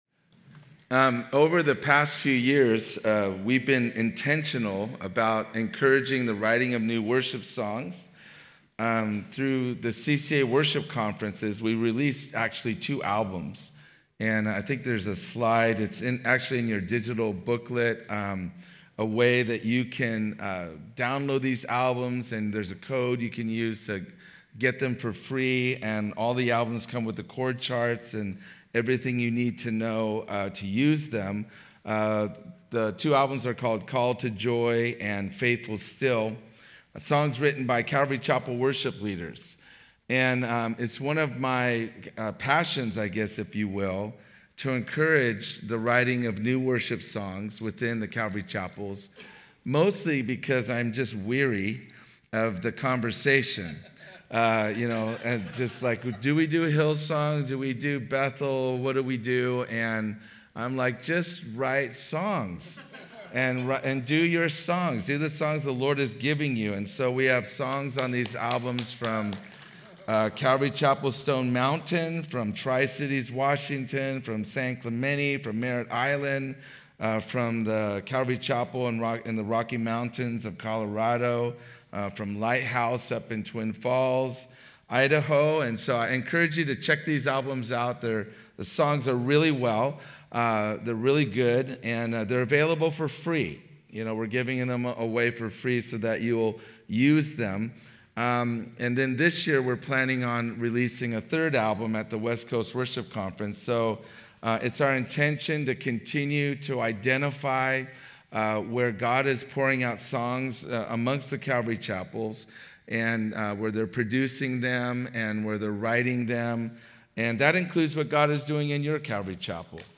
Home » Sermons » A New Song of Praise
2025 DSWC Conference: Worship Conference Date